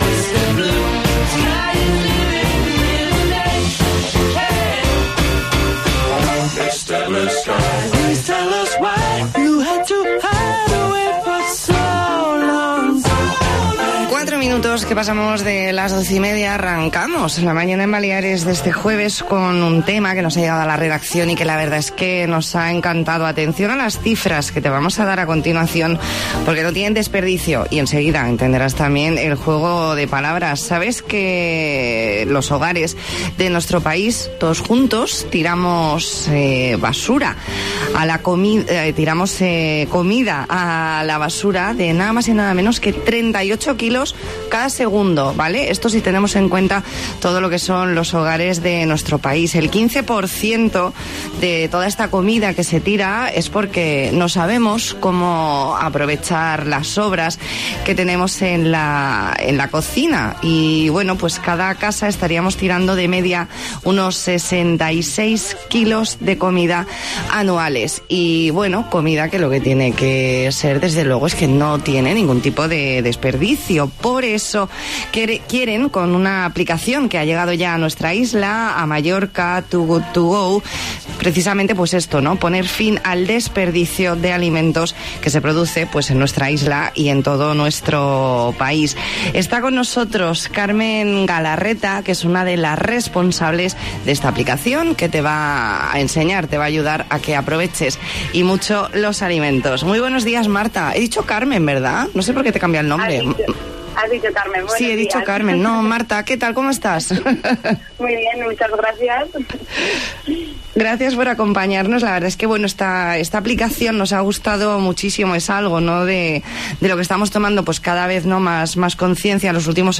Entrevista en La Mañana en COPE Más Mallorca, jueves 23 de mayo de 2019.